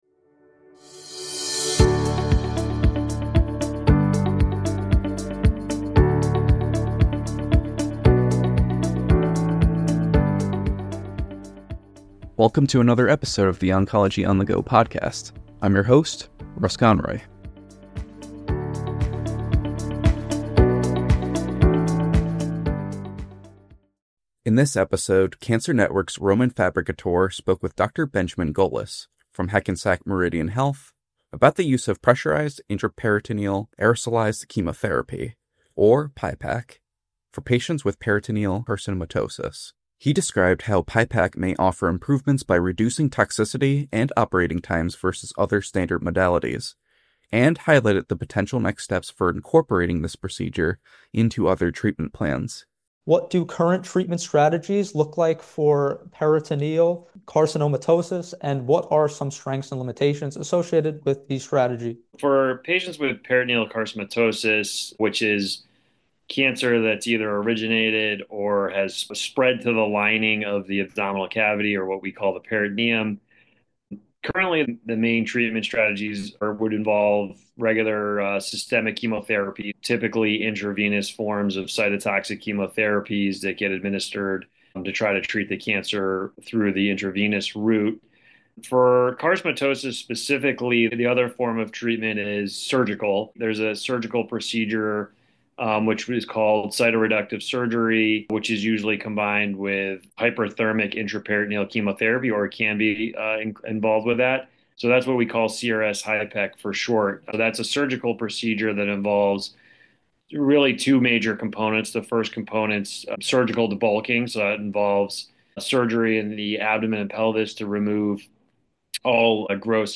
In a conversation